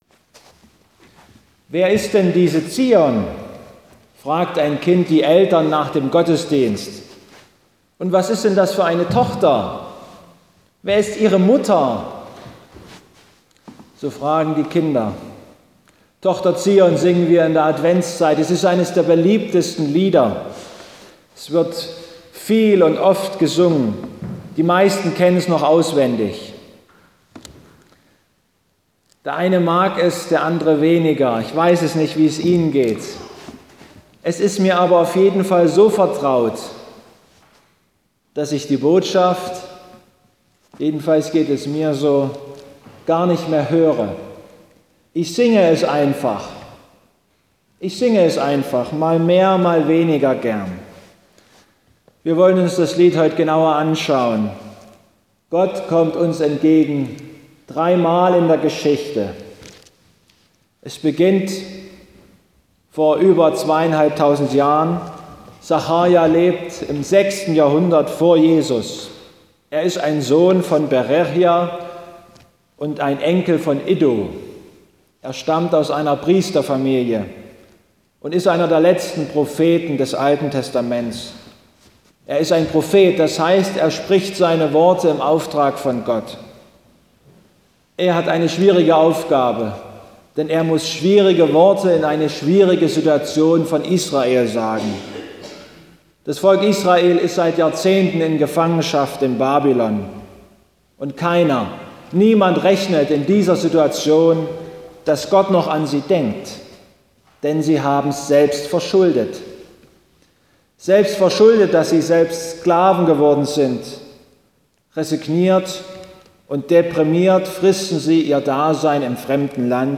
(Zu hören sind auch die Stangengrüner Sänger ...)